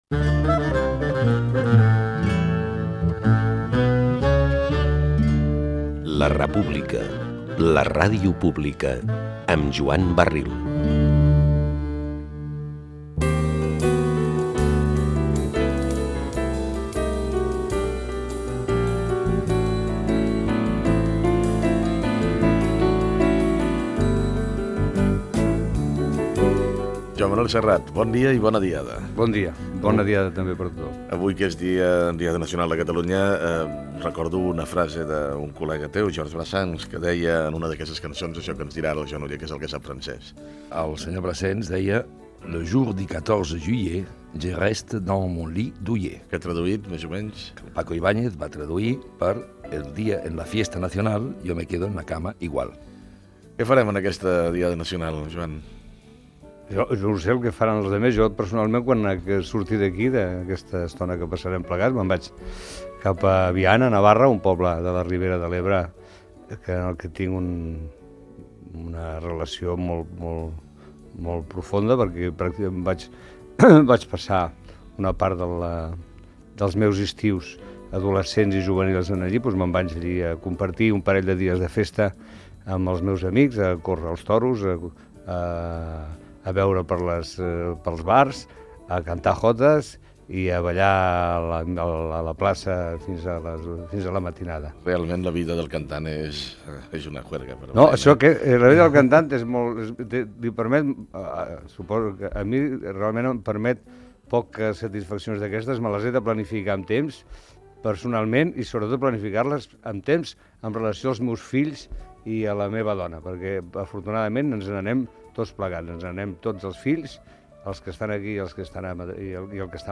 Info-entreteniment